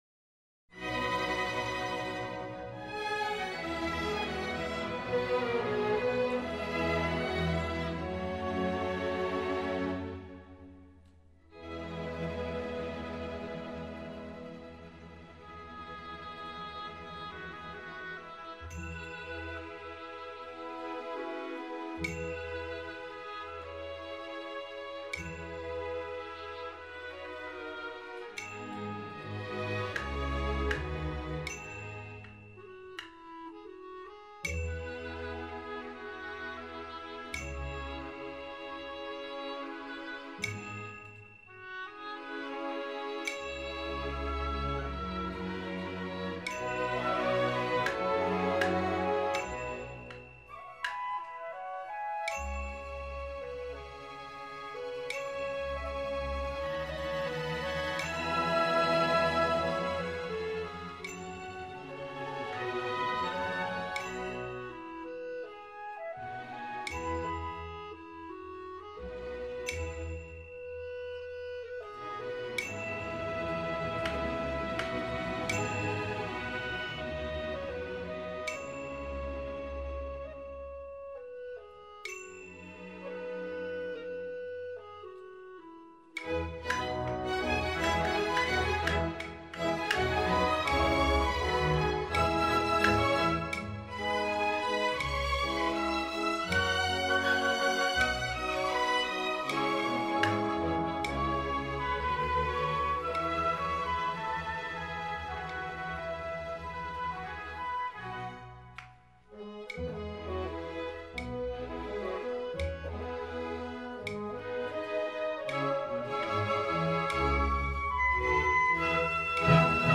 录音地点：中国上海